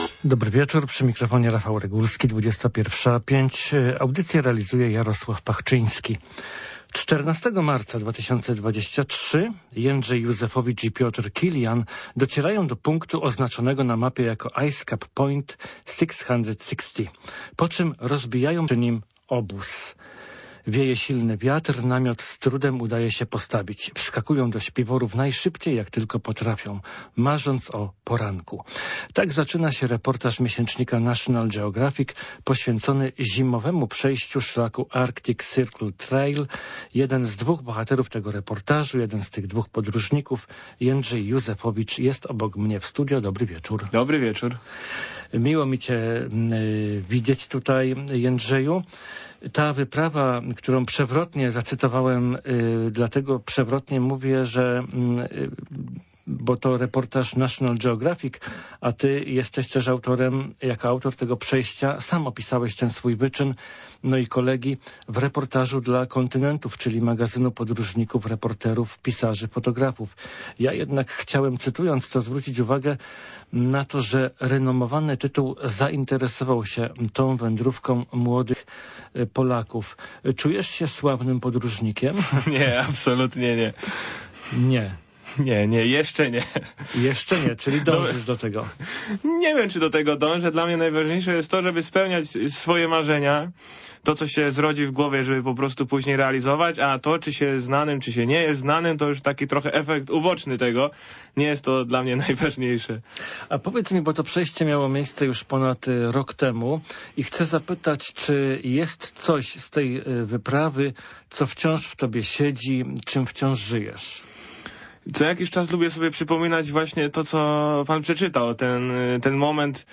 Oto rozmowa